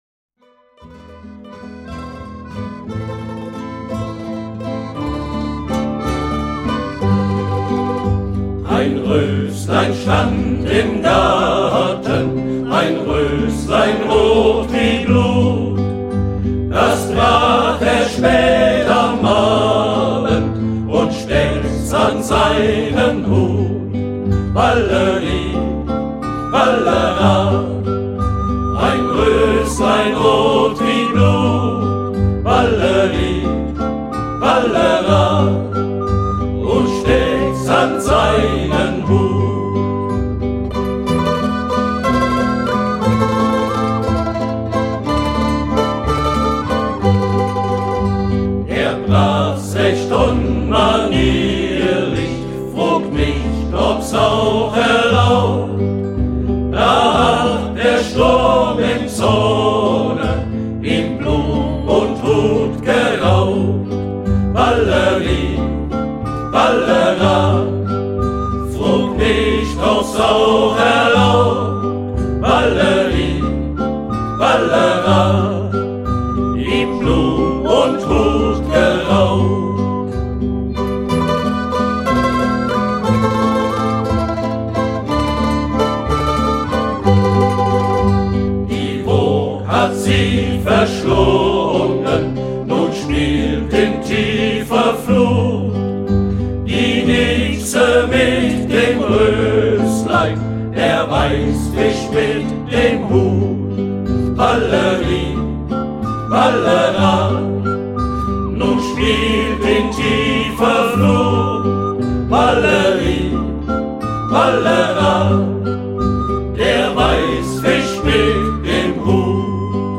Ballade
Ballade - 6/8